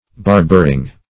Barbering - definition of Barbering - synonyms, pronunciation, spelling from Free Dictionary